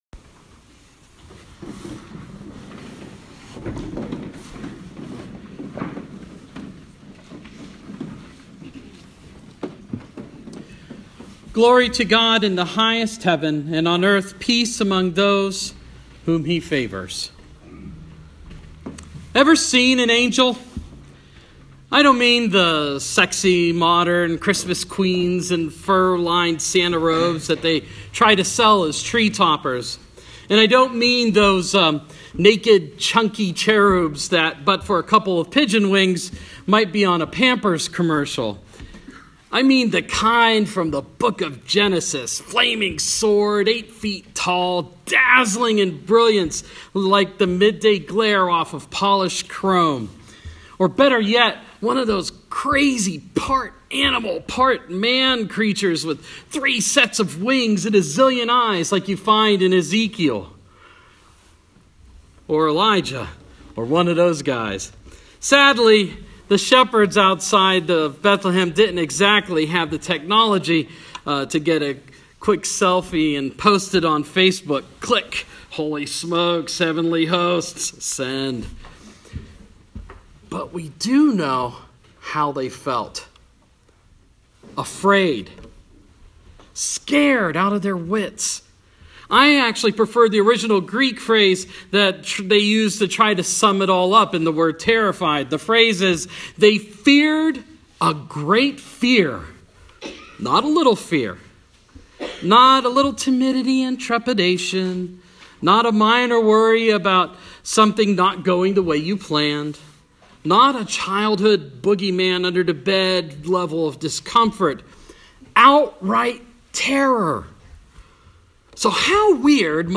christmas-eve-2015.m4a